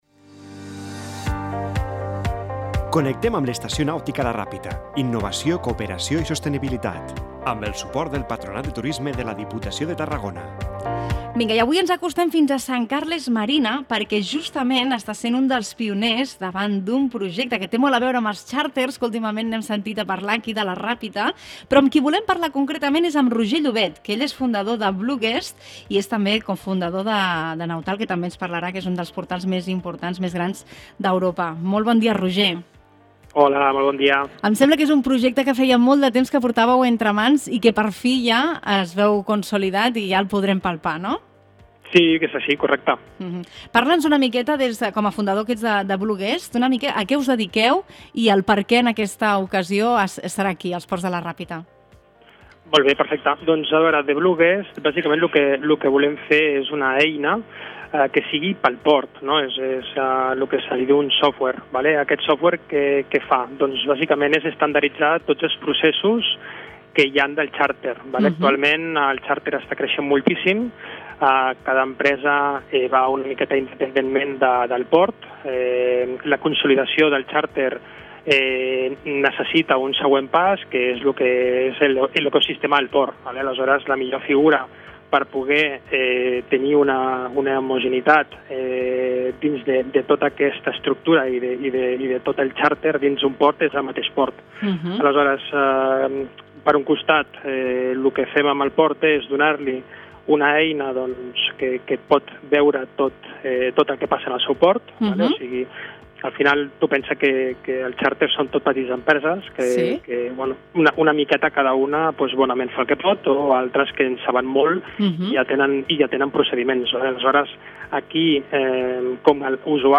Des de l’Estació Nàutica de la Ràpita ens apropem fins a Sant Carles Marina, una de les empreses pioneres en consolidar els xàrters al port de la Ràpita.